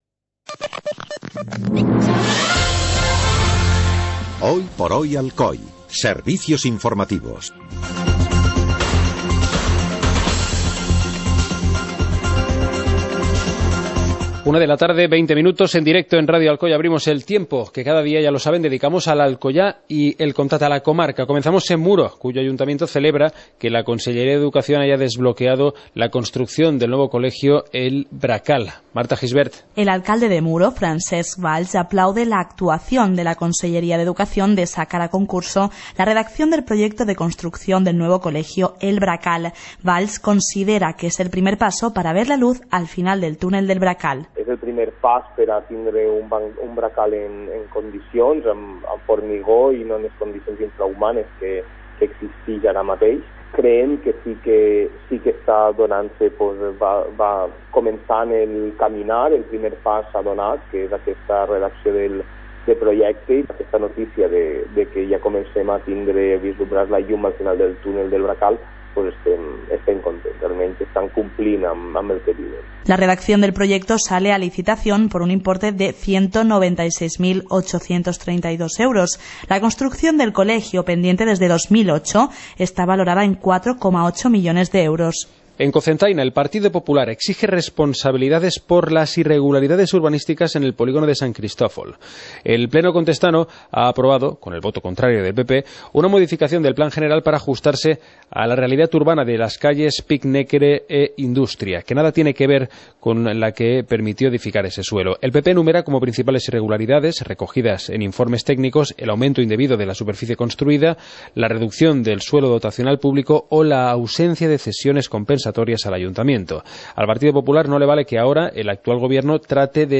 Informativo comarcal - martes, 17 de mayo de 2016